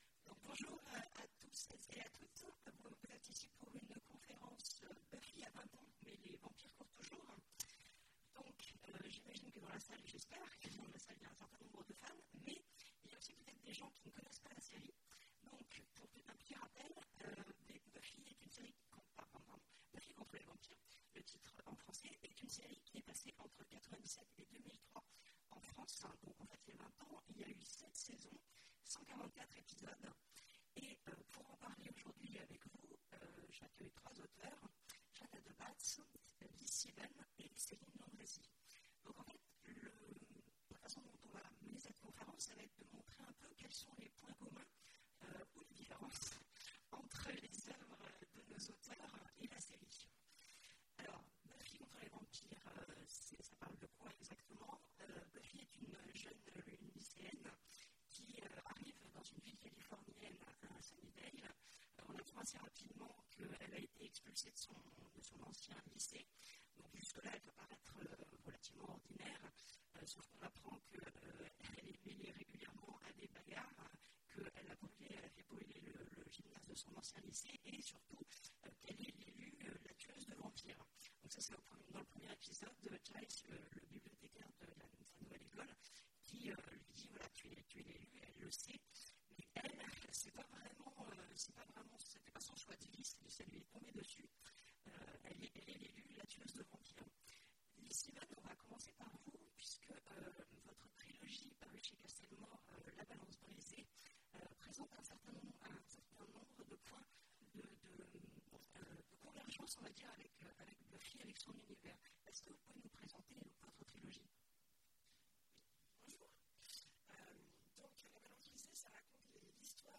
Imaginales 2017 : Conférence Buffy a 20 ans !